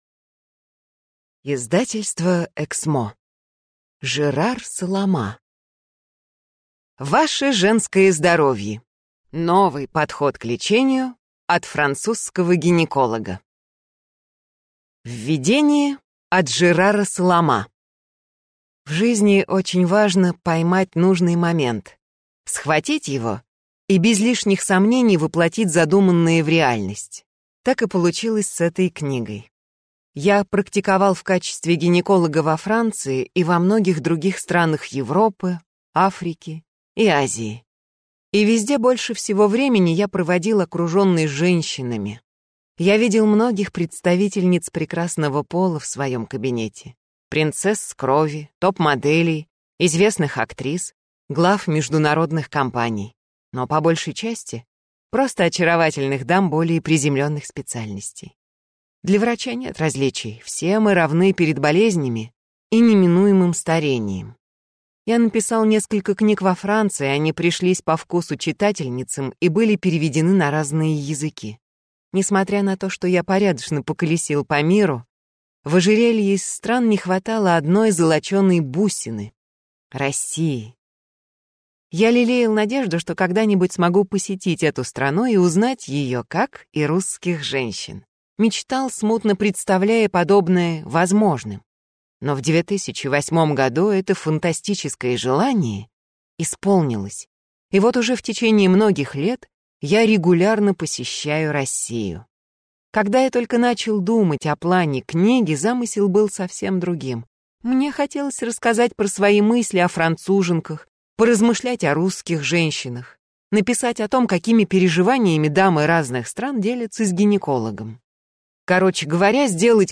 Аудиокнига Ваше женское здоровье. Новый подход к лечению от французского гинеколога | Библиотека аудиокниг